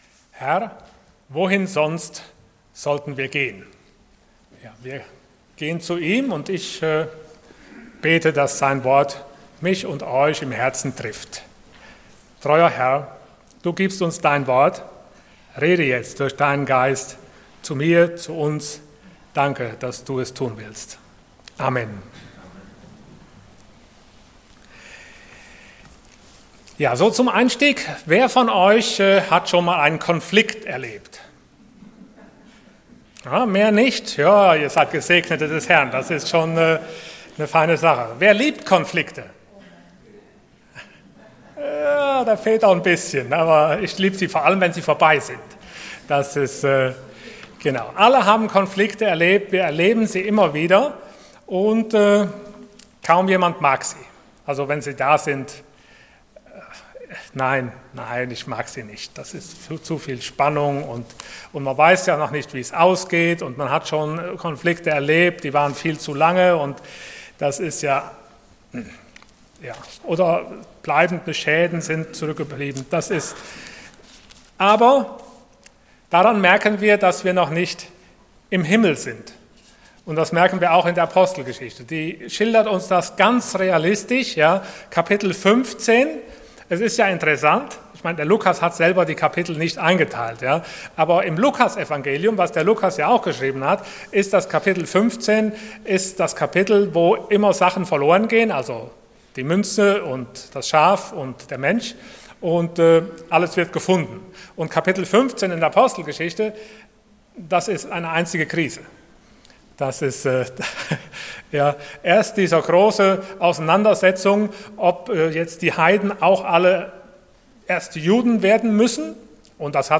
Ihr werdet meine Zeugen sein Passage: Acts 15:36-41 Dienstart: Sonntag Morgen %todo_render% Getrennte Wege « Ein Konflikt und seine Lösung Wozu der Heilige Geist?